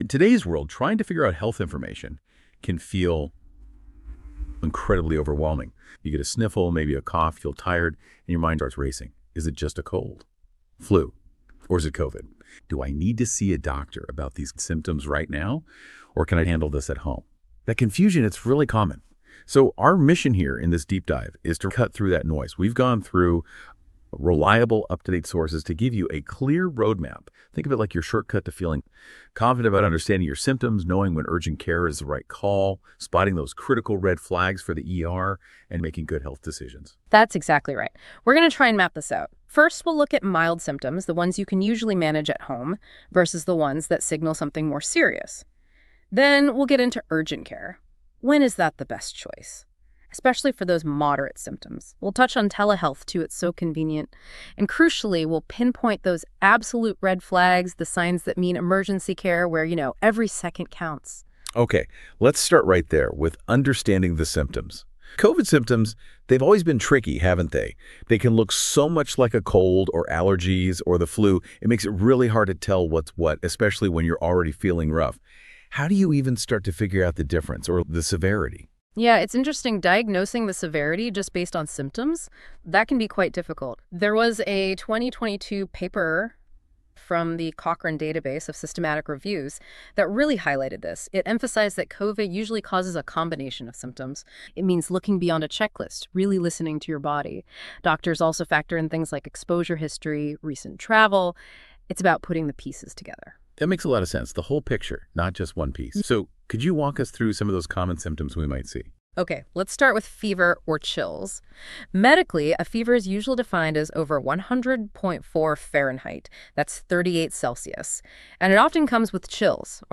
Listen to a discussion on getting help for covid When to go to urgent care for COVID-19 Go to urgent care if you have moderate COVID-19 symptoms and need testing or want to talk to a doctor.